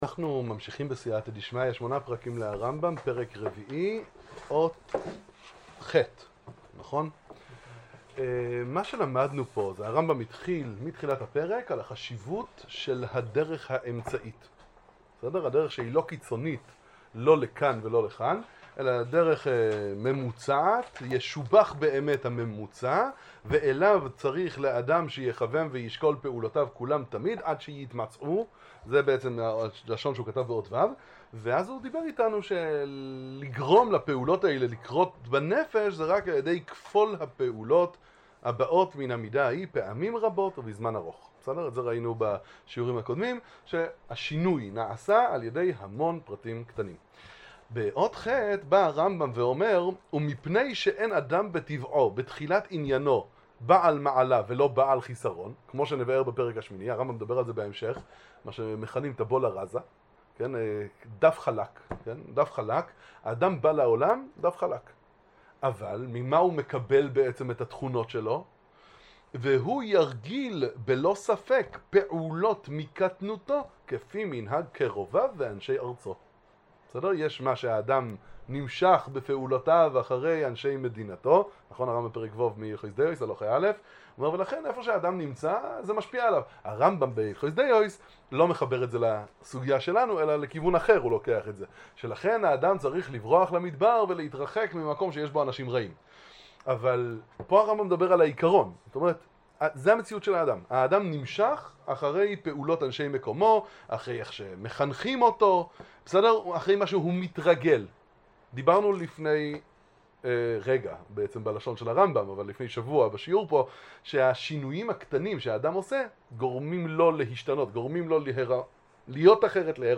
שיעורים במוסר ומחשבה